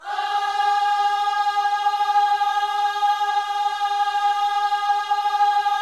OHS G#4A  -R.wav